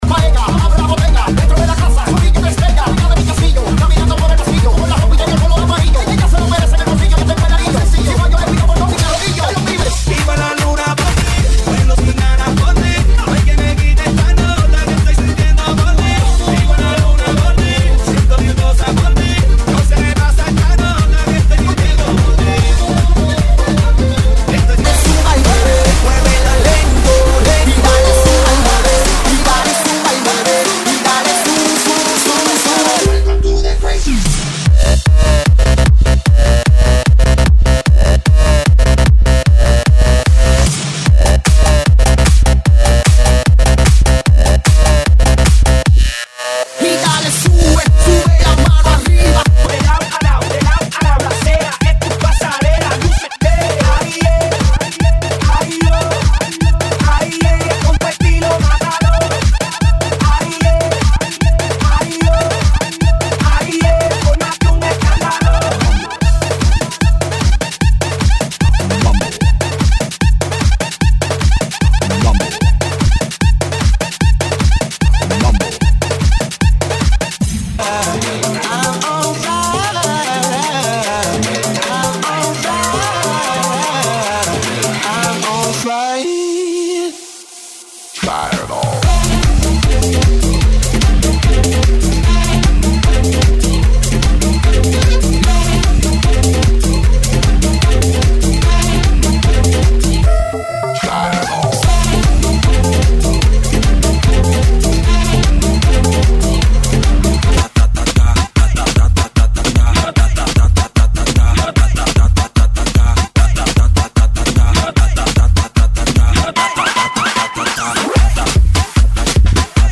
GENERO: REGGAETON REMIX